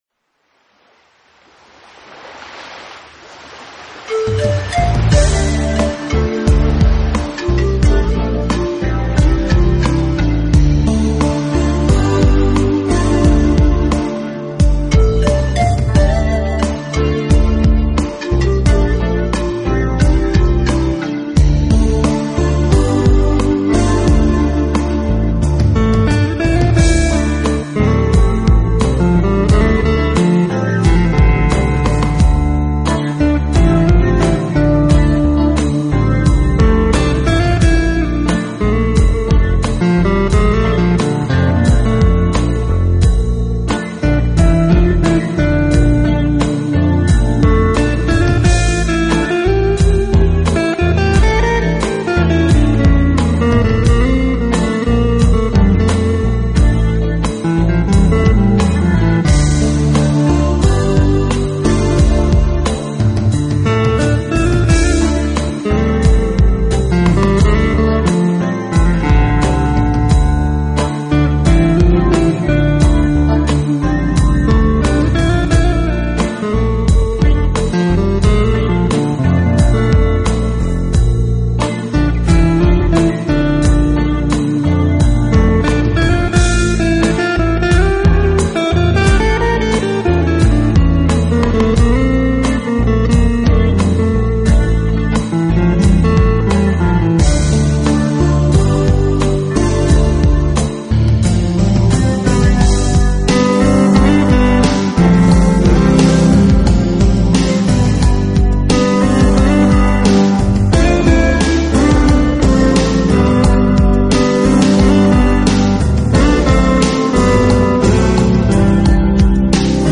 音乐类型: 轻音乐